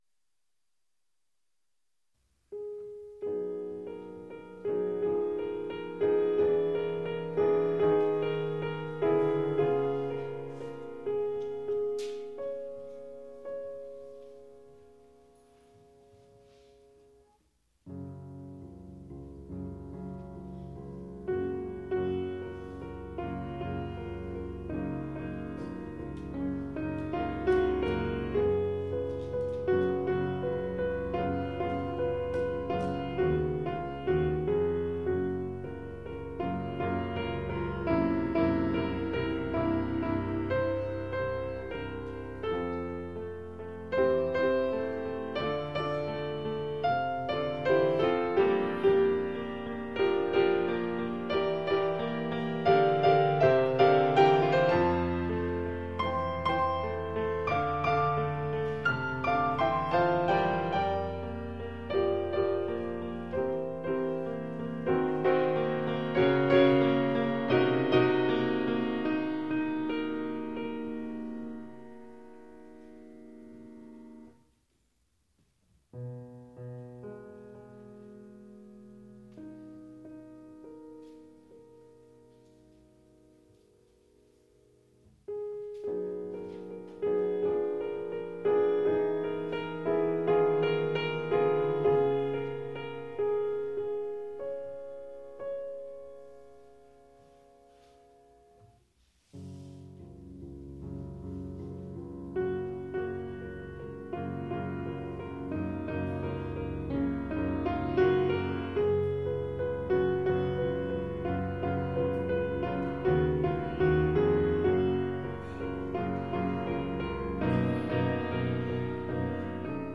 Nocturne. 2.6 Mbyte.
Live Recording  - Rome 1994